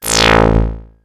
RetroGamesSoundFX
Robot3.wav